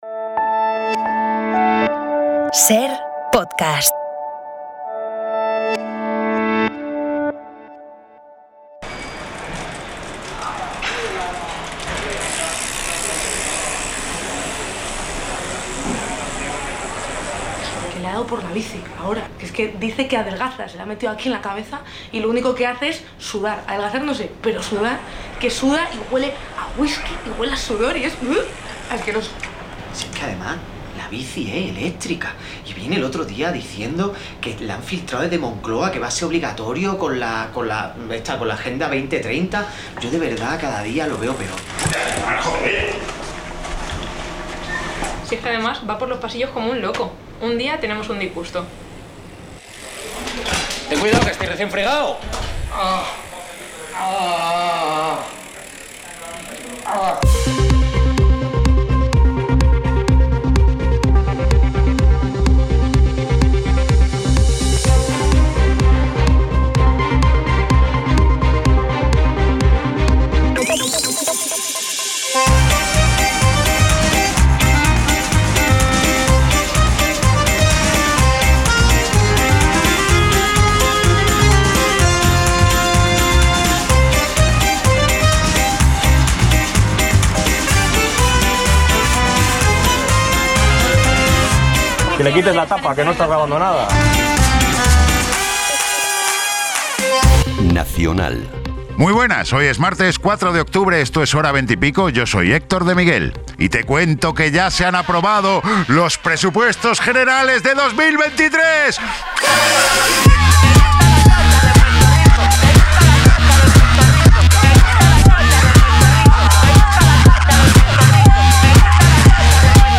Audio de Humor en la Cadena SER en Podium Podcast